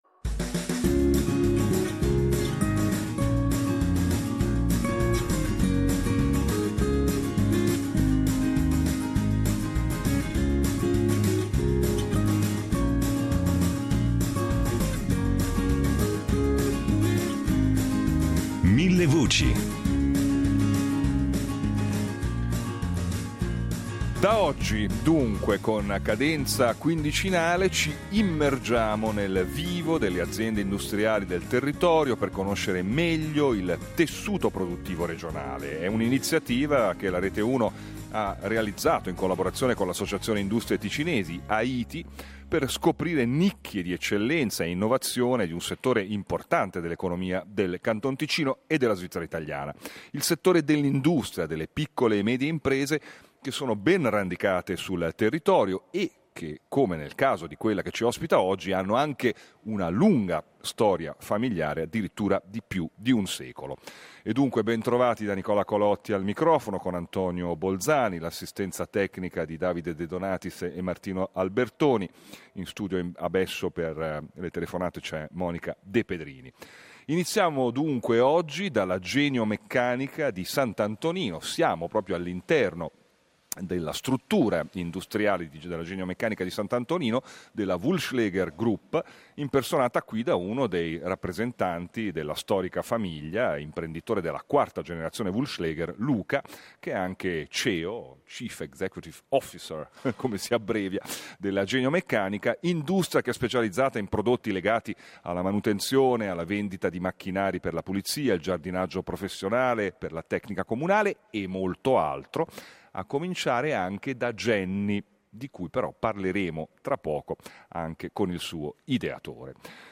Da mercoledì 18 settembre, con cadenza quindicinale, Millevoci è in diretta dall’interno di aziende industriali attive nei più disparati settori economici, con l’obiettivo di mettere in luce punti forti e criticità del tessuto produttivo regionale. Il ciclo di emissioni, proposto in collaborazione con AITI (Associazione Industrie Ticinesi), vuole presentare l’attività di ricerca e produzione di aziende di punta e particolarmente innovative e attive sui mercati globali.